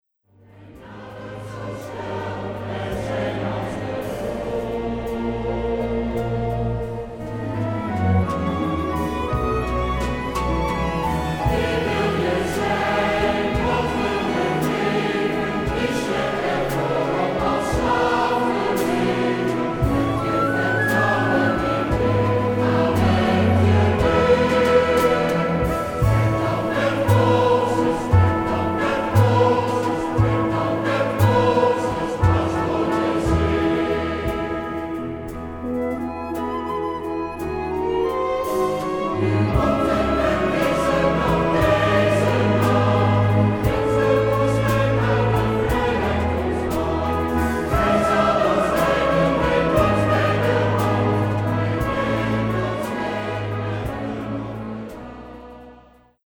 Oratorium over het leven van Mozes